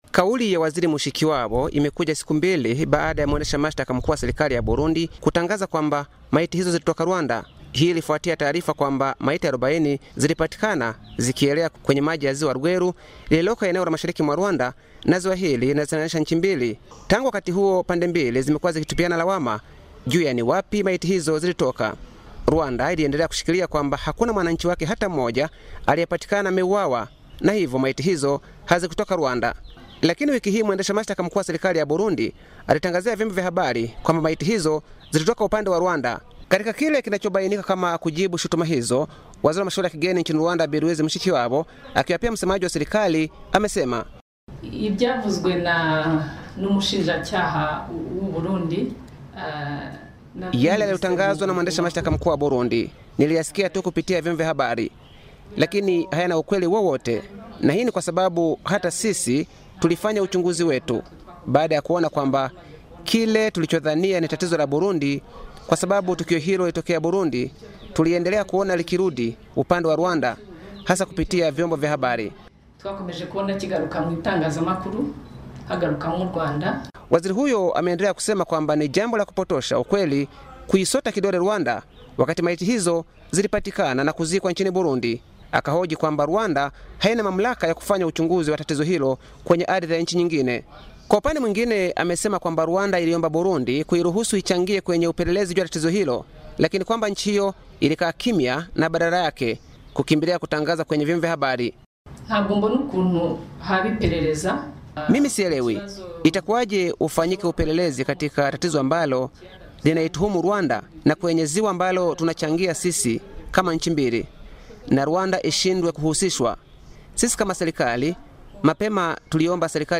CHANZO:DW REDIO